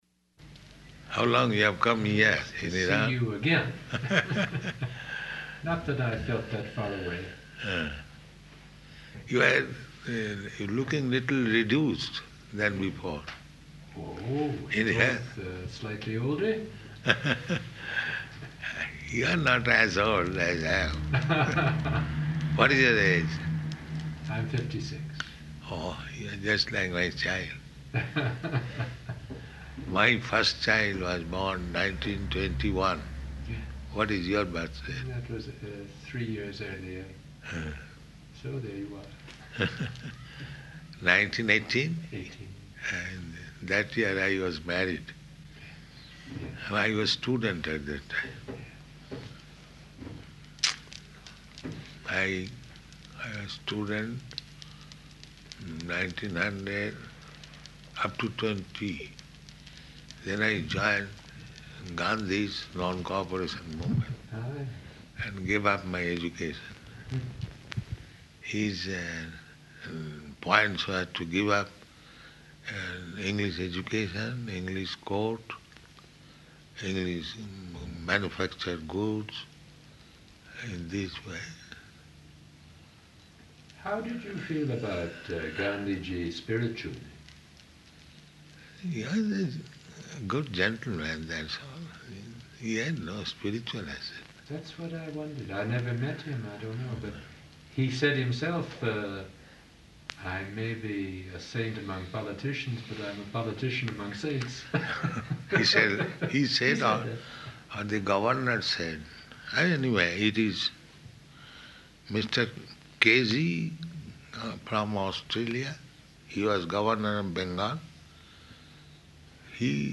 Room Conversation with Canadian Ambassador to Iran
Room Conversation with Canadian Ambassador to Iran --:-- --:-- Type: Conversation Dated: March 13th 1975 Location: Tehran Audio file: 750313R1.TEH.mp3 Prabhupāda: How long you have come here in Iran?